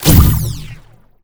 sci-fi_weapon_blaster_laser_boom_04.wav